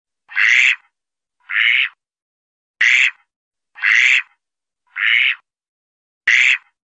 绿头鸭叫声